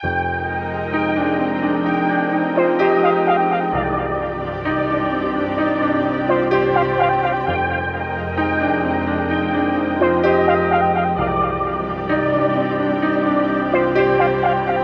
MELODY LOOPS
Astral (129 BPM – Cm)
UNISON_MELODYLOOP_Astral-129-BPM-Cm.mp3